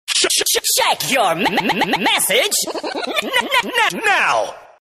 File Type : Sms ringtones